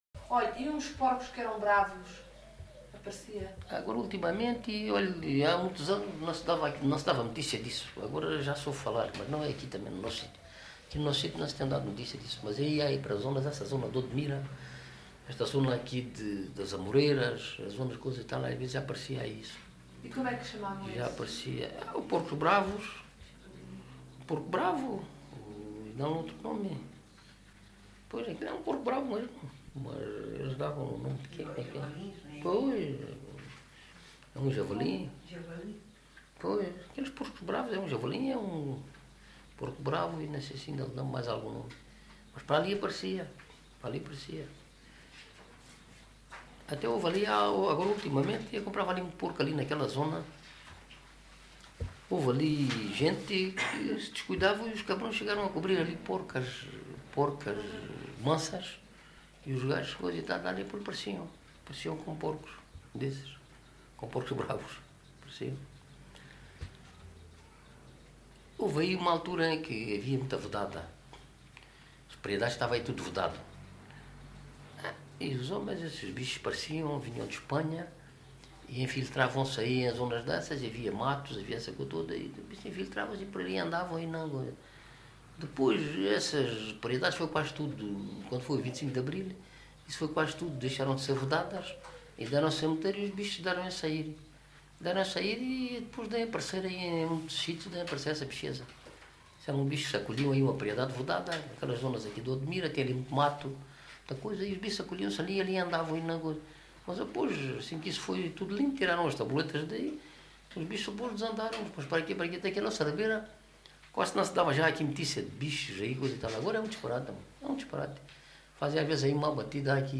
LocalidadeMontes Velhos (Aljustrel, Beja)